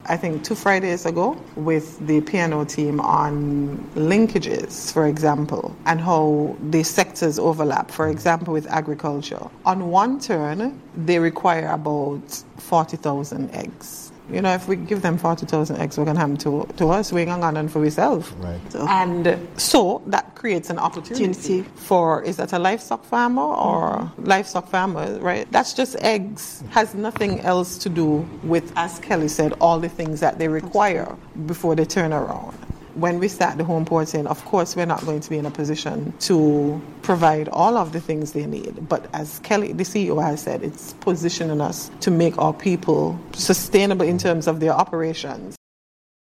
Federal Minister of Tourism, the Hon. Marsha Henderson gave this example: